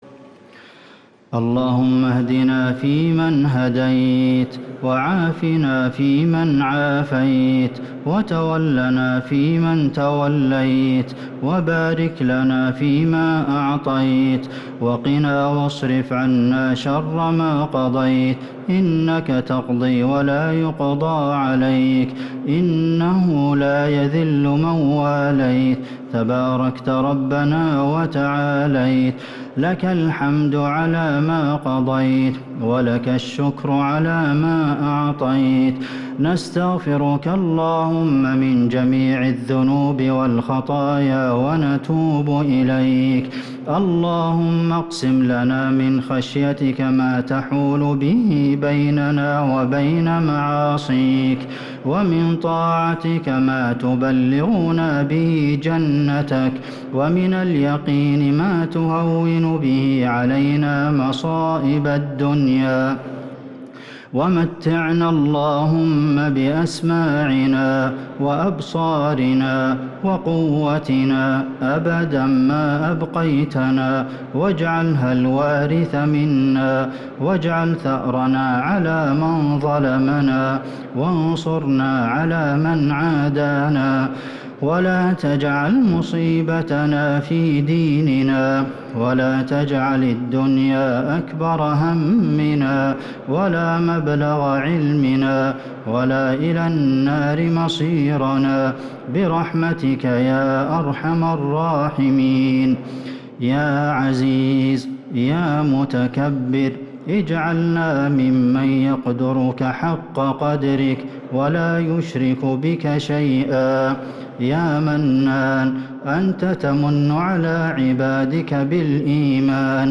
دعاء القنوت ليلة 6 رمضان 1444هـ | Dua 6 st night Ramadan 1444H > تراويح الحرم النبوي عام 1444 🕌 > التراويح - تلاوات الحرمين